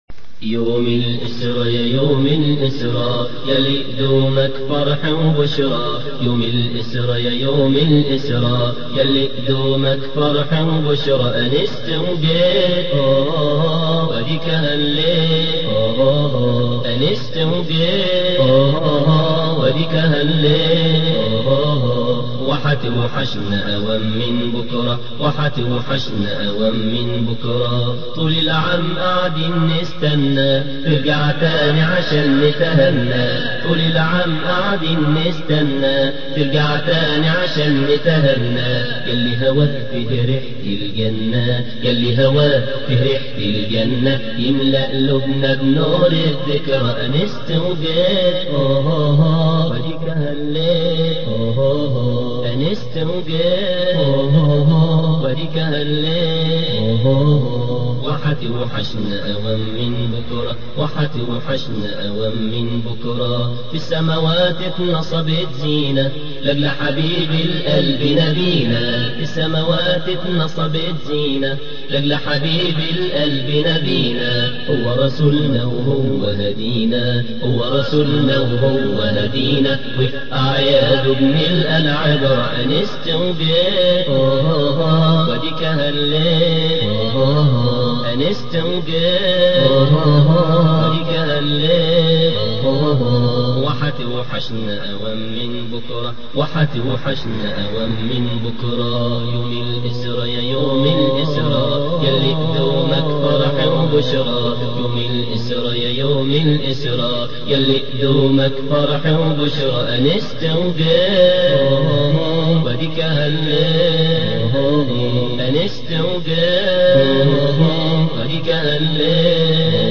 مزج بين عذوبة الصوت وجمال الإلقاء واللحن